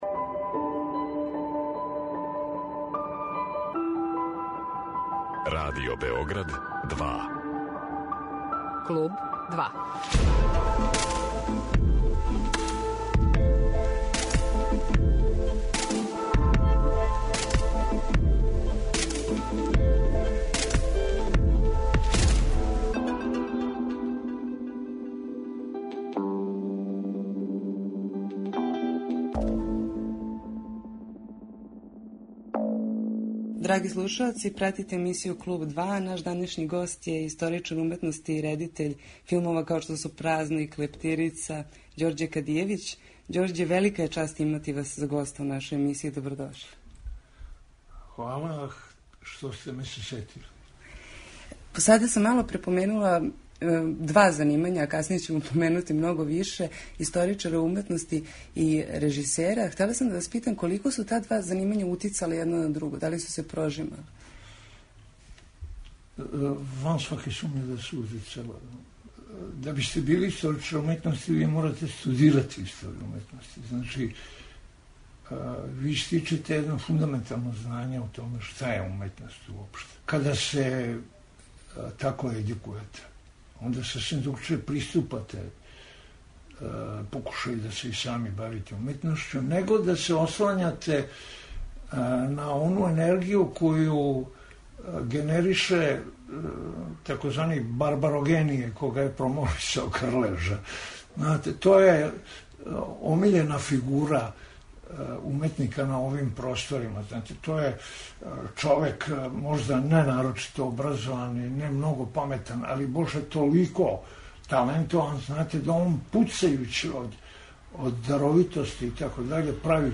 Данашњи гост емисије Клуб 2 је редитељ Ђорђе Кадијевић.